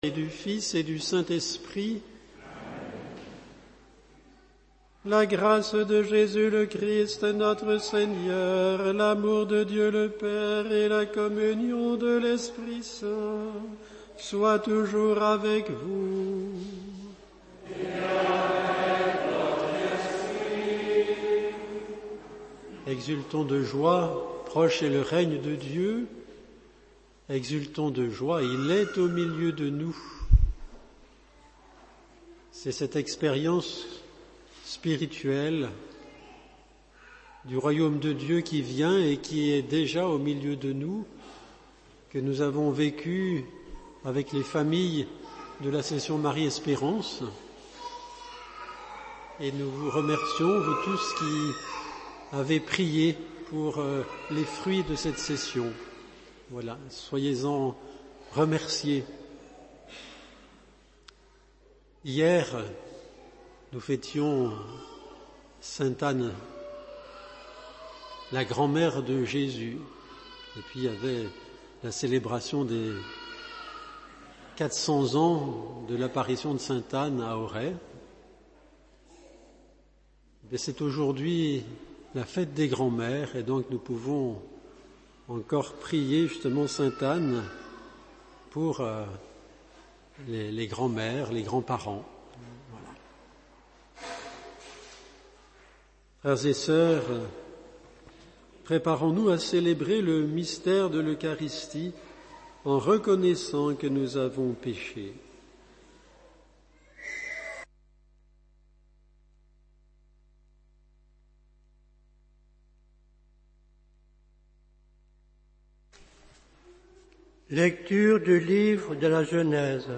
Homélie Les vacances sont ces périodes de l’année qui offrent des temps libres et nous donnent la possibilité de vaquer à des occupations que nous ne pouvons pas faire pendant le reste de l’année.